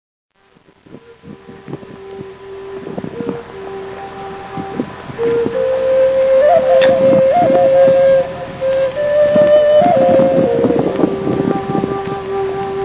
A mile deep gorge lays almost at your feet and the sun is setting.
One year a man setup his portable amplifier and played till dark.
gcflute.mp3